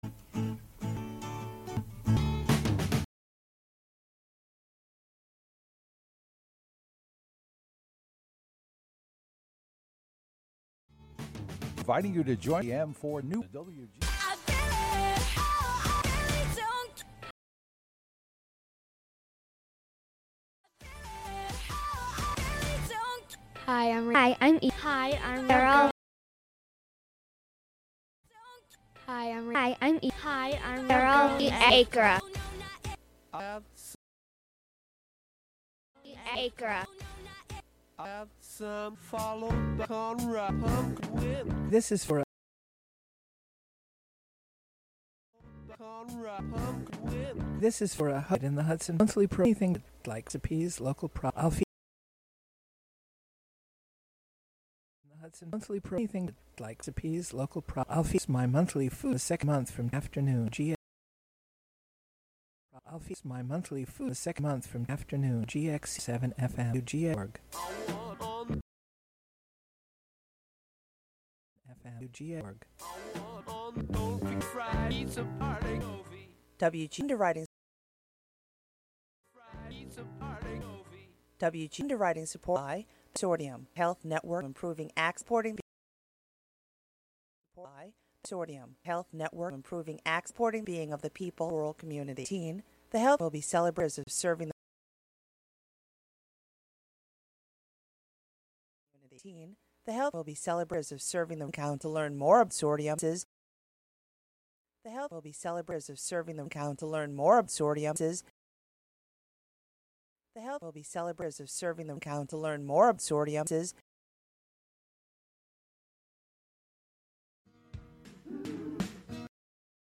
The show features interviews and discussion with political figures and newsmakers on a range of topics of importance to Columbia County, N.Y., and beyond.